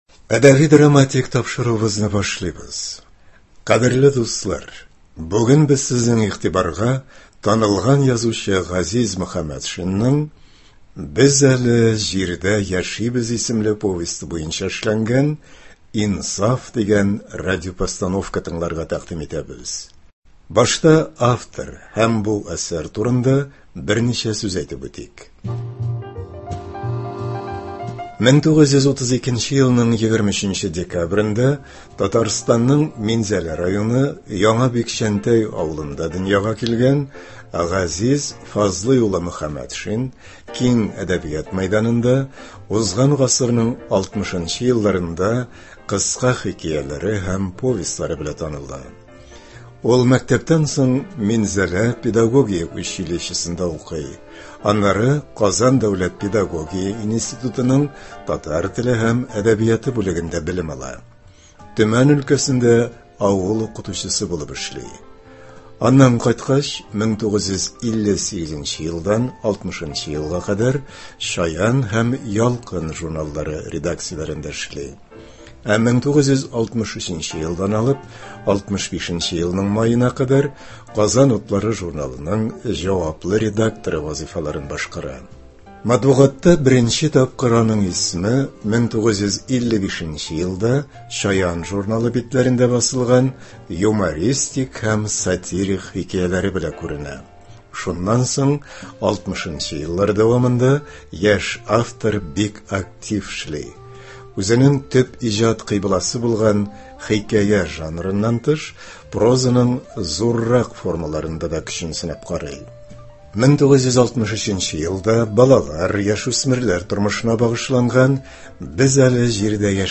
Радиоспектакль (28.01.24)
Икенчедән, әлеге тарихи язмада бик күп мәшһүр сәхнә осталарының тавышлары сакланып калган.
Рольләрне Г.Камал исемендәге татар дәүләт академия театры артистлары башкара.